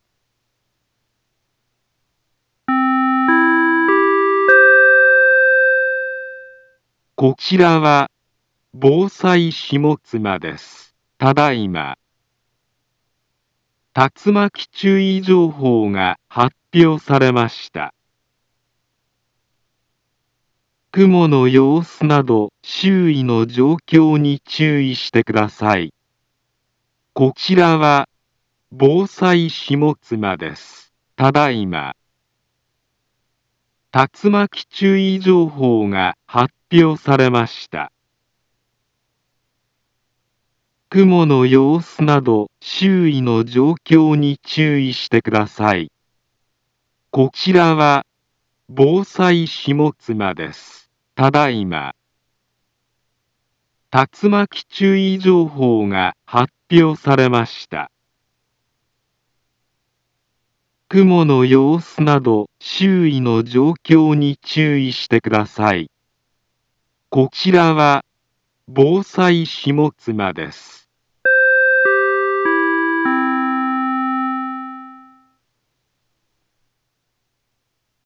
Back Home Ｊアラート情報 音声放送 再生 災害情報 カテゴリ：J-ALERT 登録日時：2023-06-28 21:25:11 インフォメーション：茨城県北部、南部は、竜巻などの激しい突風が発生しやすい気象状況になっています。